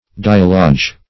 Diallage \Di*al"la*ge\, n. [NL., fr. Gr. ? interchange, change,